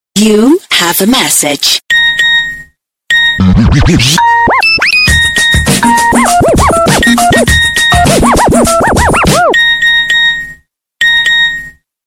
Nokia Sms RingTones